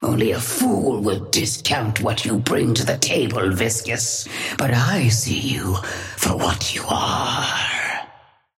Sapphire Flame voice line - Only a fool will discount what you bring to the table, Viscous, but I see you for what you are.
Patron_female_ally_viscous_start_10.mp3